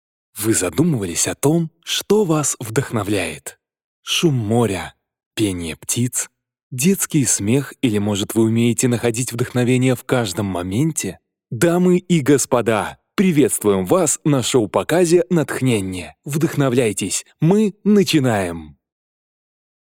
Муж, Закадровый текст/Средний
RME Babyface pro, LONG, DBX, Digilab, Neumann TLM 103, 023 Bomblet, ARK 87, Oktava MK-105, sE 2200